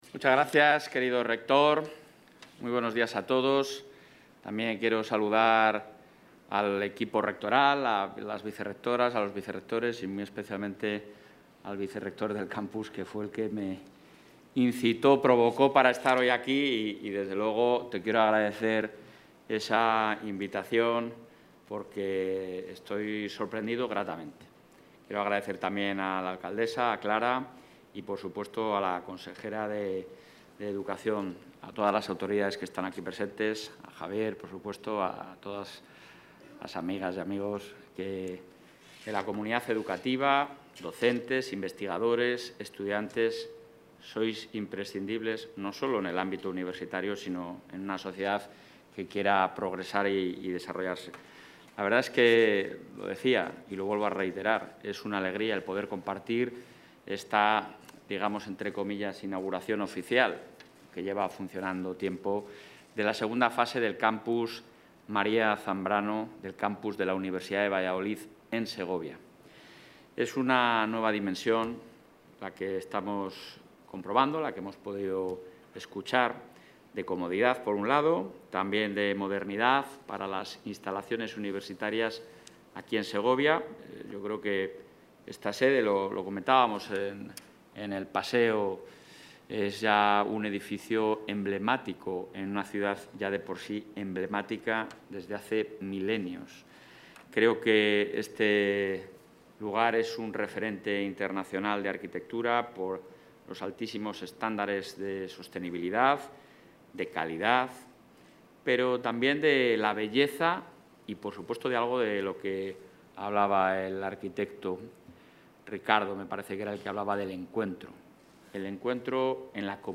Declaraciones del presidente de la Junta.
“La decisión está tomada”, ha subrayado durante la inauguración oficial de la segunda fase del centro universitario.